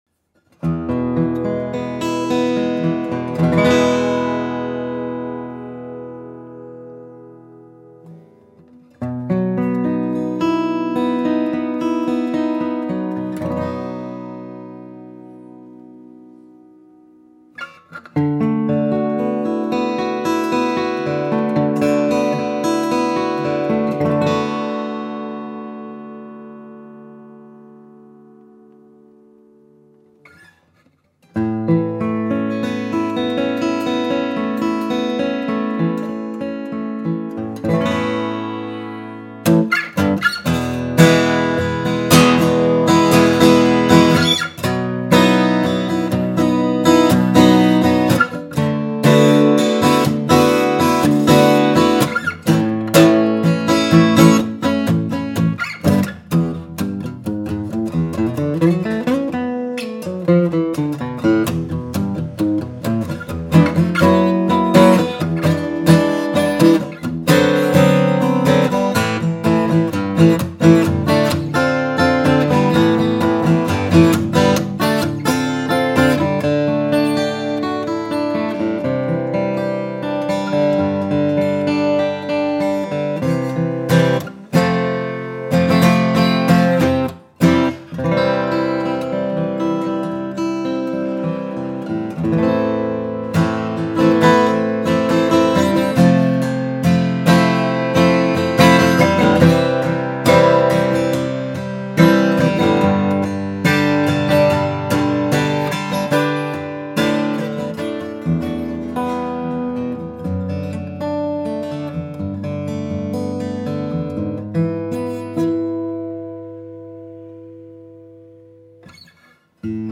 Martin DSS-2018 Custom Shop Slope Shoulder Dreadnought. Light weight and bold tone with all the classic elements of the first Dreadnought.
Sound-impression-Martin-DSS-2018.mp3